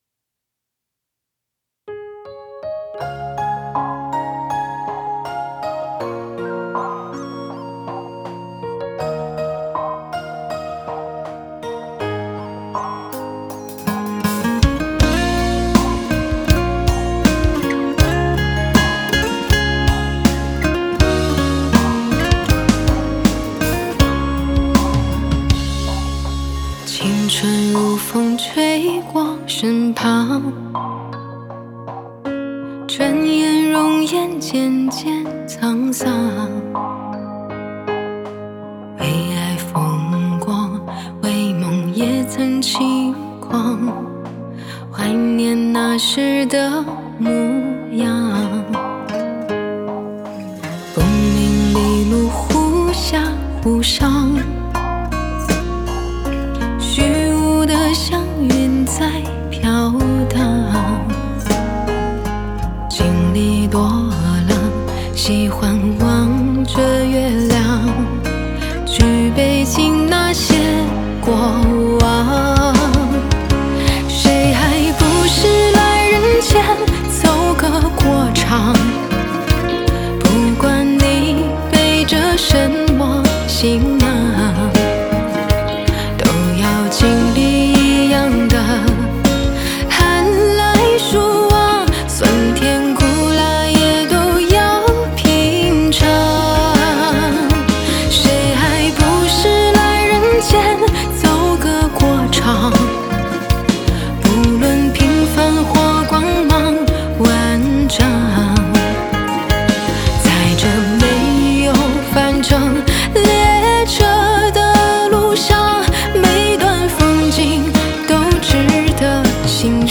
Ps：在线试听为压缩音质节选，体验无损音质请下载完整版
吉他
和声